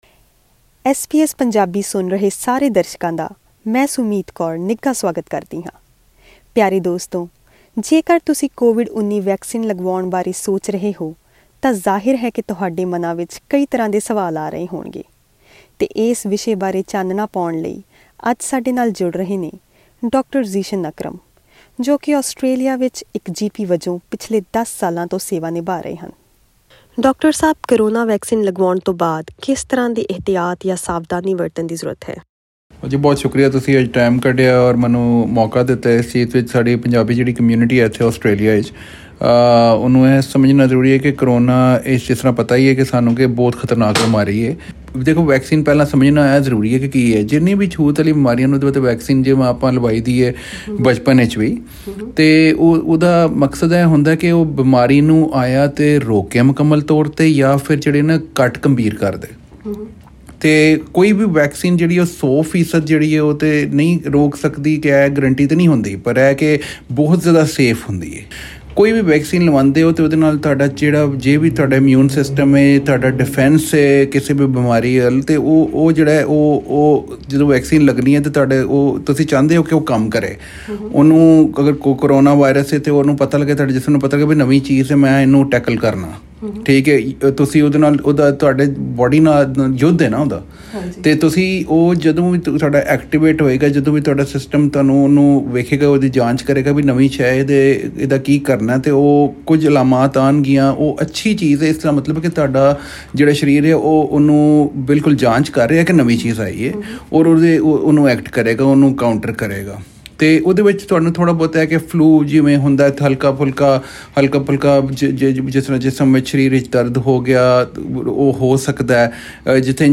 ਕਰੋਨਾ ਟੀਕਾਕਰਣ ਦੇ ਚਲਦਿਆਂ ਅਸੀਂ ਕੋਵਿਡ ਵੈਕਸੀਨ ਲਗਵਾ ਚੁੱਕੇ ਕੁਝ ਲੋਕਾਂ ਨਾਲ ਗੱਲਬਾਤ ਕੀਤੀ ਤਾਂ ਪਾਇਆ ਕਿ ਹਰ ਕਿਸੇ ਦਾ ਟੀਕੇ ਪ੍ਰਤੀ ਵੱਖਰਾ ਪ੍ਰਤੀਕਰਮ ਹੈ - ਕੁਝ ਲੋਕਾਂ ਨੂੰ ਕੋਈ ਪਰੇਸ਼ਾਨੀ ਨਹੀਂ ਆਉਂਦੀ ਤੇ ਕਈਆਂ ਨੂੰ ਕਾਫੀ ਦਿੱਕਤ ਦਾ ਸਾਹਮਣਾ ਕਰਨਾ ਪੈ ਰਿਹਾ ਹੈ |